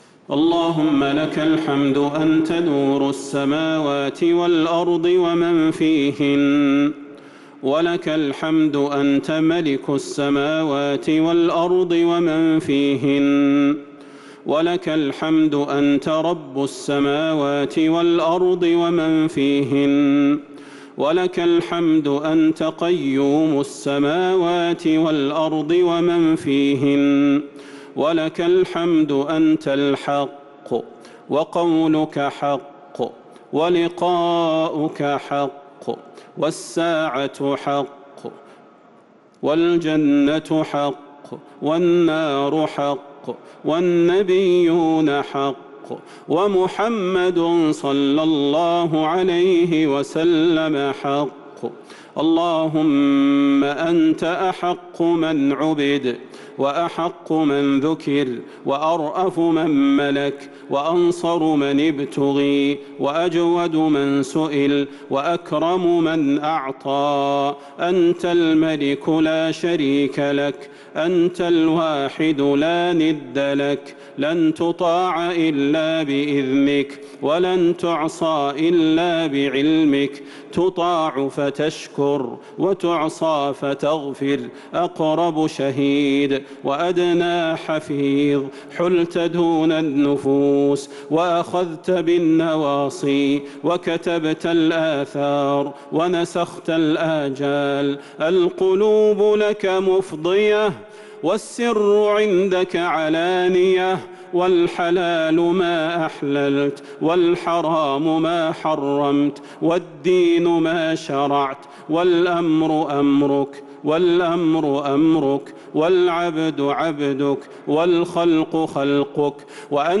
دعاء القنوت ليلة 21 رمضان 1443هـ | Dua for the night of 21 Ramadan 1443H > تراويح الحرم النبوي عام 1443 🕌 > التراويح - تلاوات الحرمين